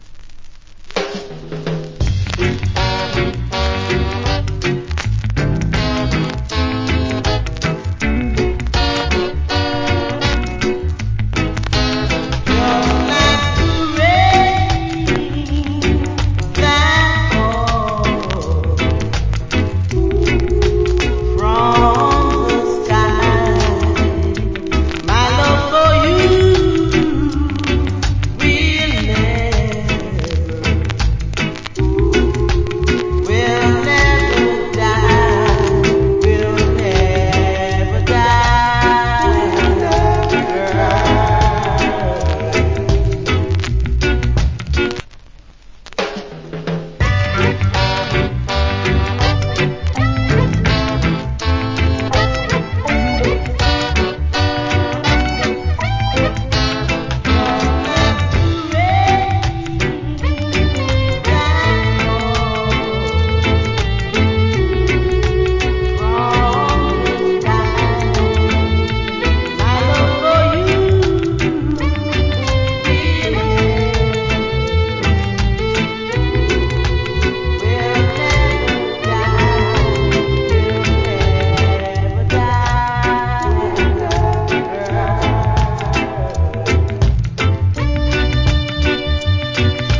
Old Hits Rock Steady Vocal.